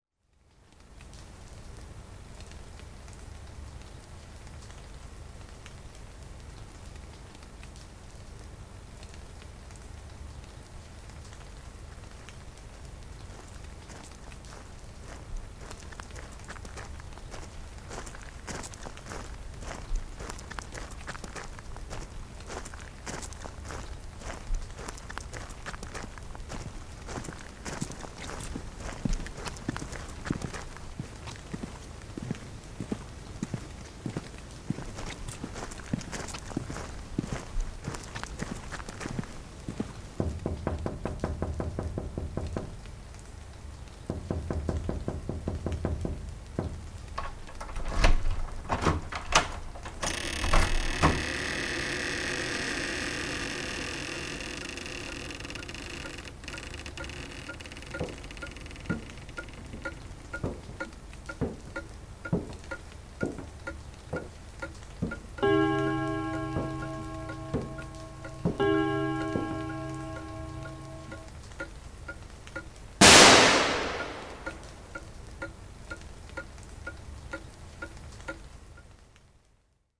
A scary Halloween night in Limerick
a soundscape mix, just for the scary fun of it.